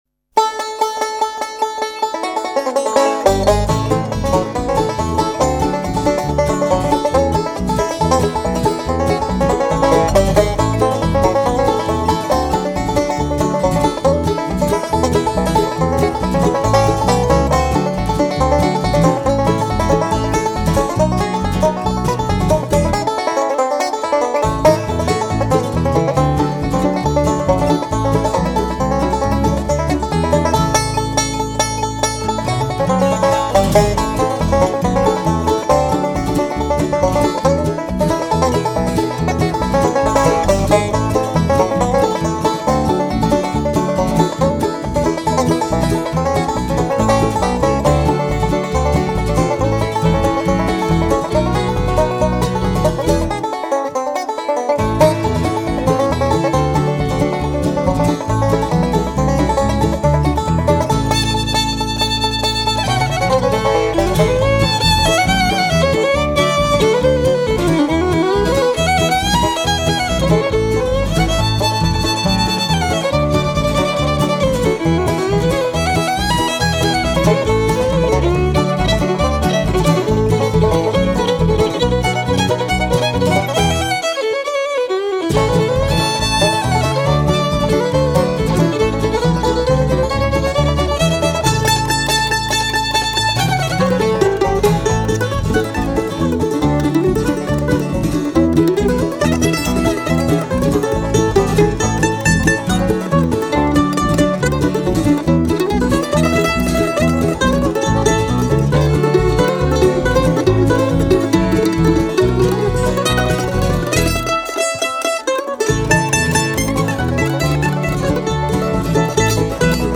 Back Porch Bluegrass Show - 03-10-17 - community radio exchange